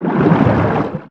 Sfx_creature_pinnacarid_swim_slow_03.ogg